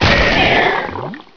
1 channel
death1.wav